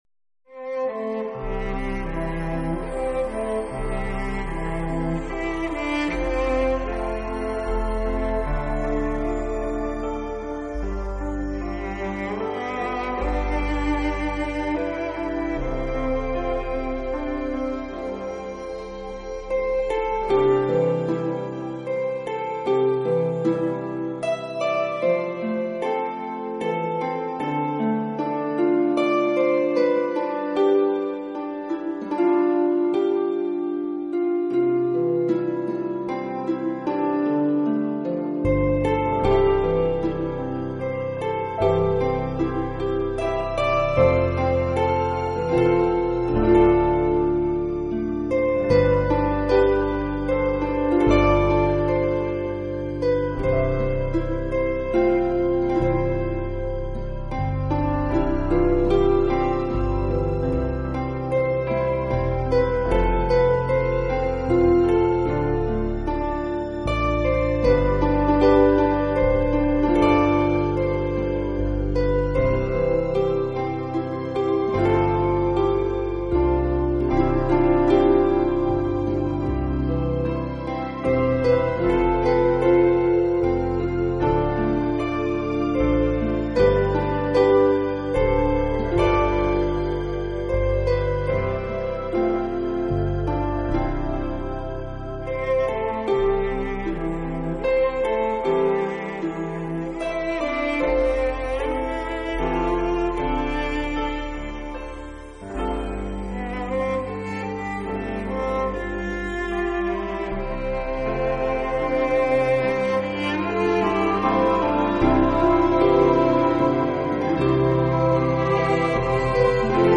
音乐风格：New Age